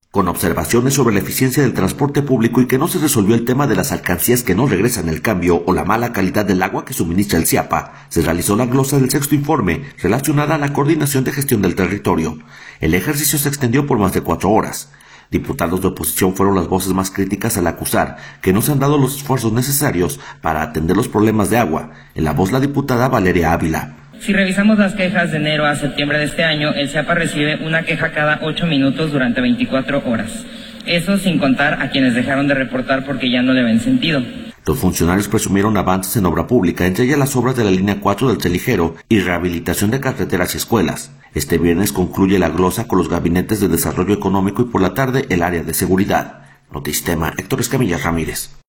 Diputados de oposición fueron las voces más críticas al acusar que no se han dado los esfuerzos necesarios para atender los problemas de agua. En la voz la diputada Valeria Ávila.